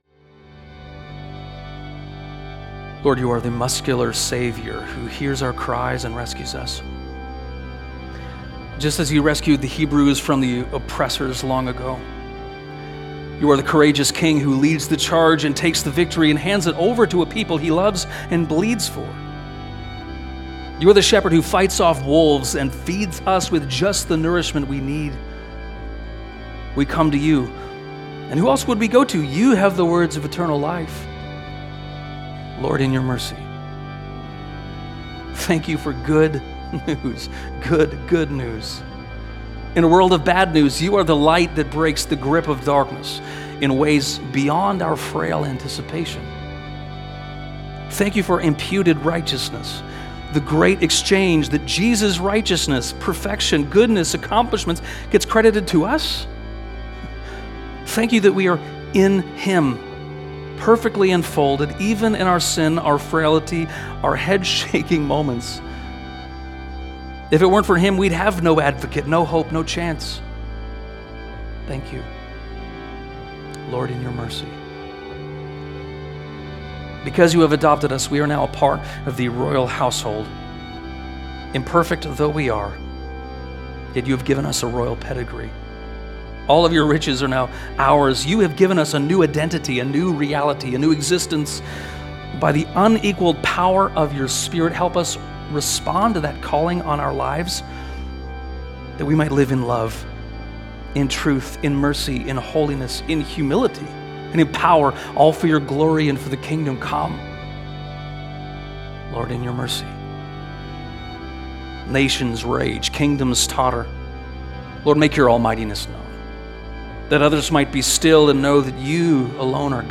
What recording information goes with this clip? Join in with this prayer from the service on July 13, 2025.